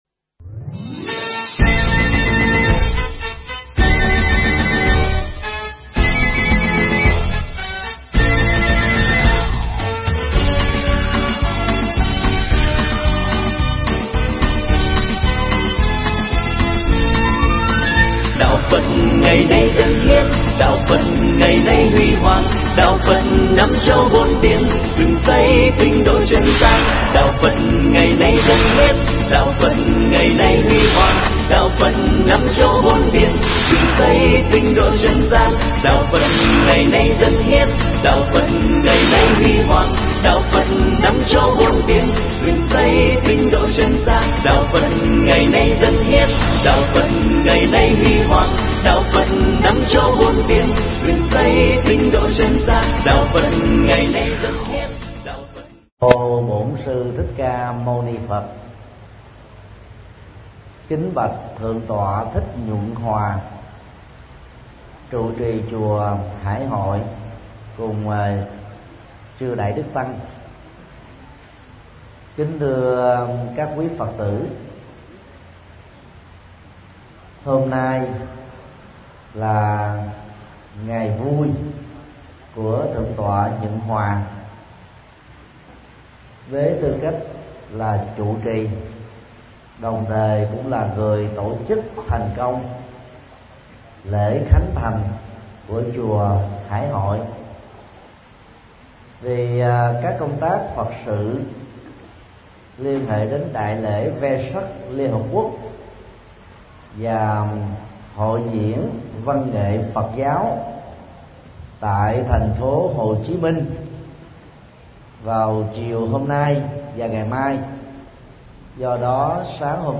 Tải mp3 Pháp Thoại Những điều nên biết về đạo Phật
Giảng tại chùa Hải Hội, Đà Nẵng, ngày 12 tháng 4 năm 2014